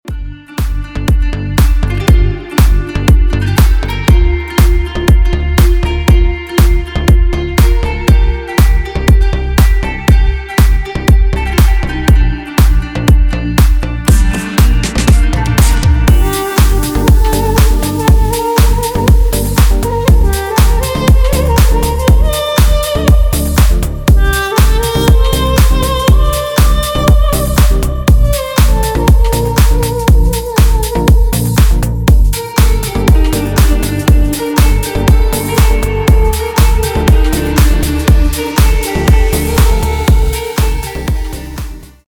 deep house
восточные мотивы
без слов
красивая мелодия
струнные
дудка
дудук
Стиль: deep house